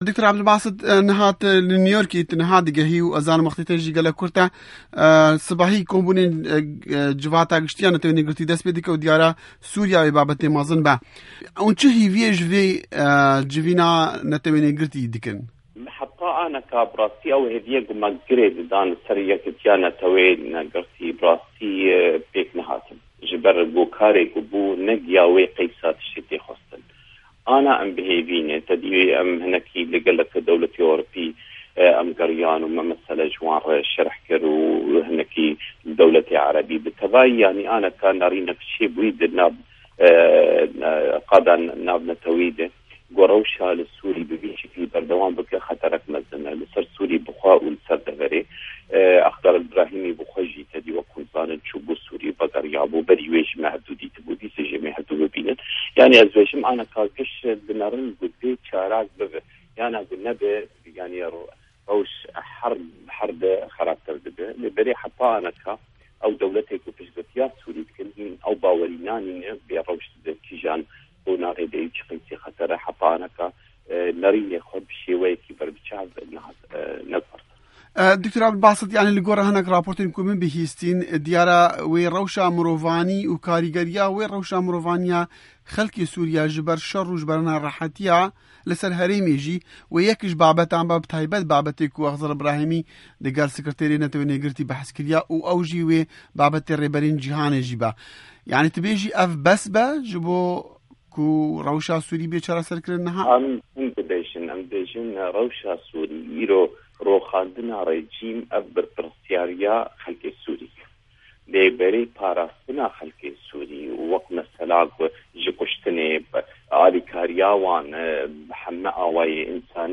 Hevpeyv'în digel Dr. Ebdulbasit Sayda